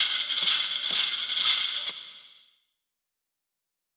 orangutan.wav